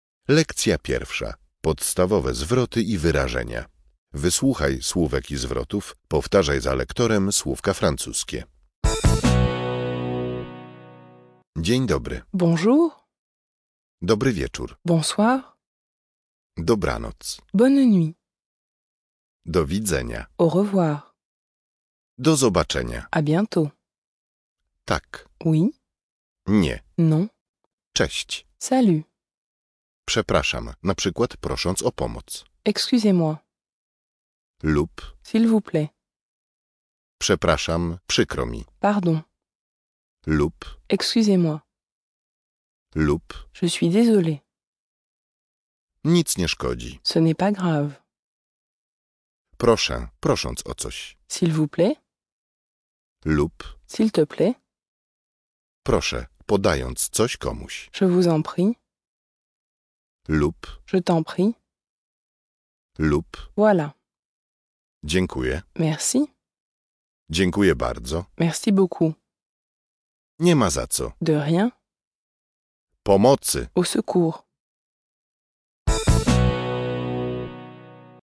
Francuski Na wakacje - audiobook